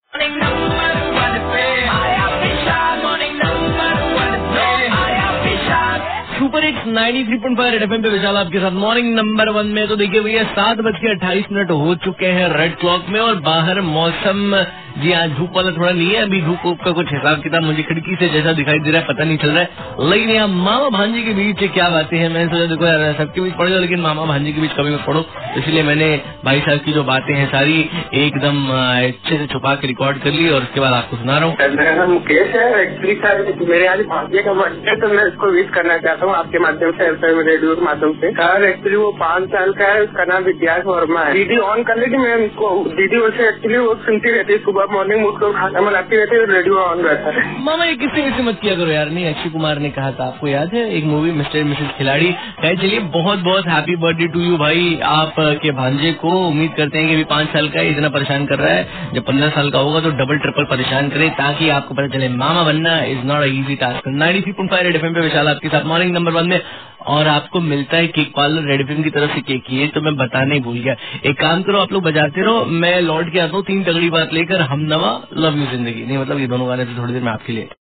Mama ne kia apni chtou si bhanji ko wish :) HAPPY VOICE